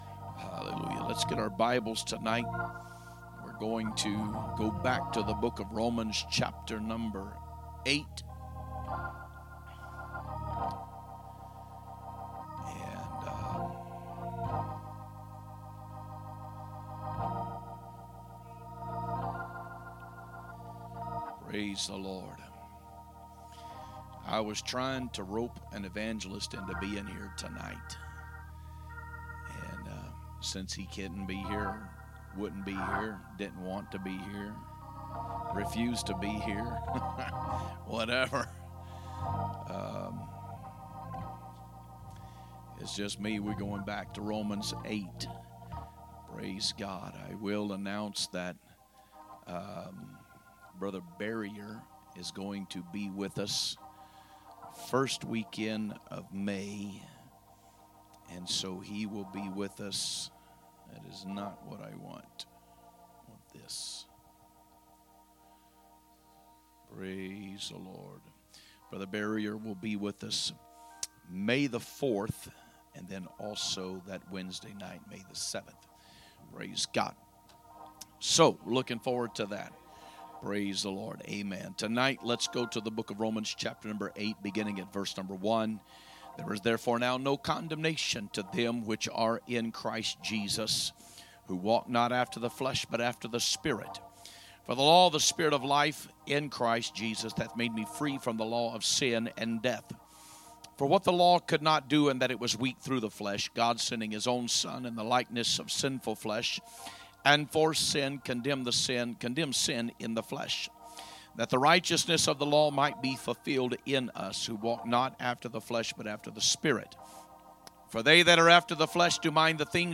A message from the series "2025 Preaching." 4/16/2025 Wednesday Service